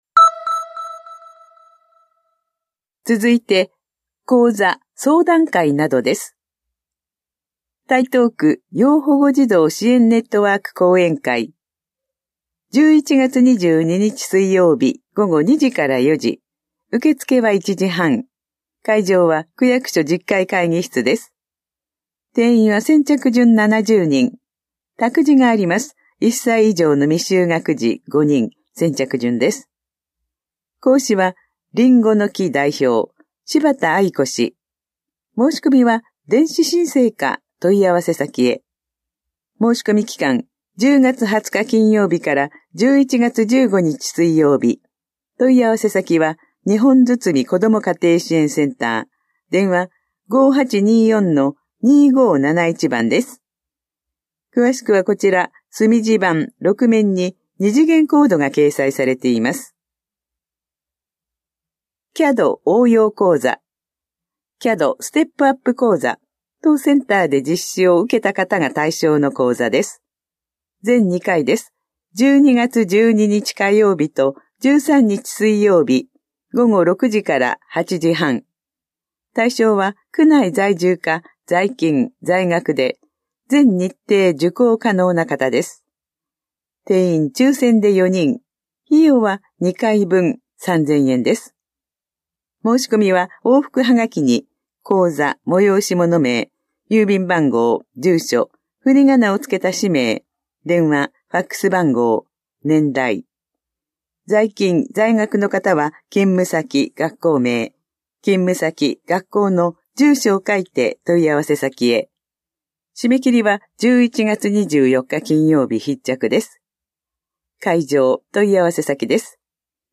広報「たいとう」令和5年10月20日号の音声読み上げデータです。